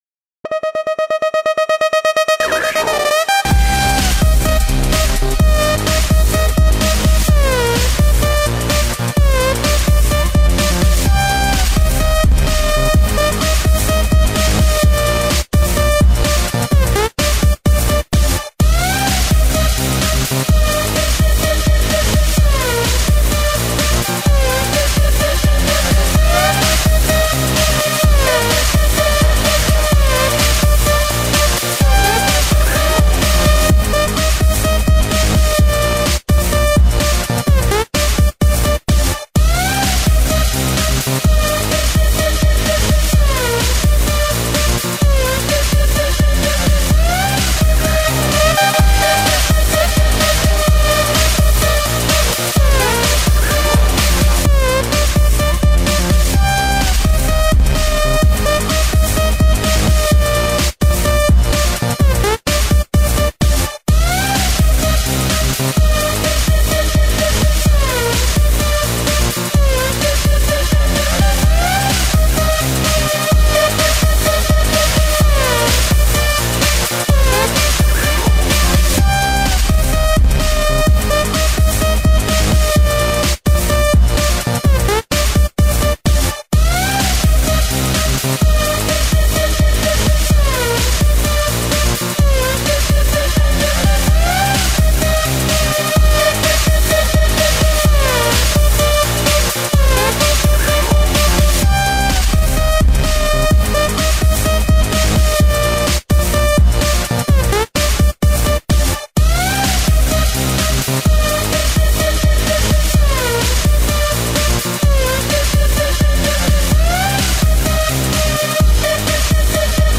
Клубняк [44]